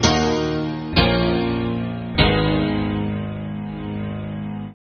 Night Rider - Deep Piano.wav